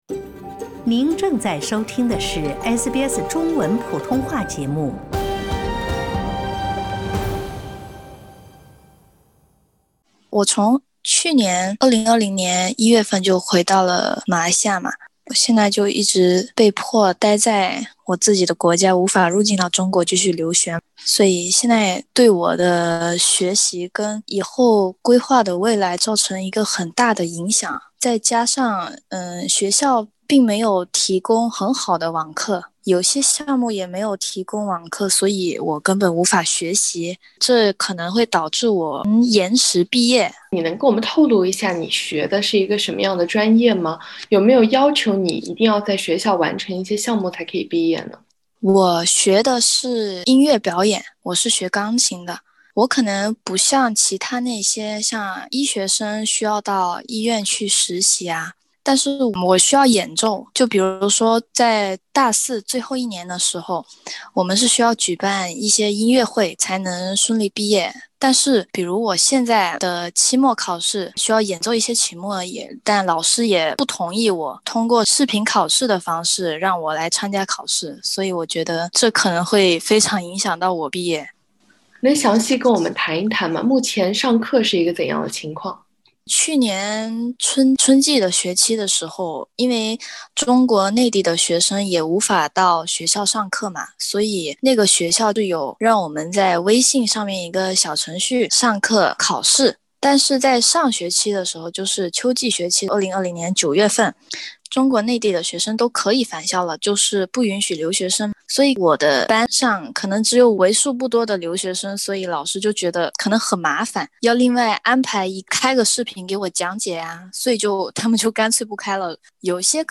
欢迎您点击首图，收听完整音频采访。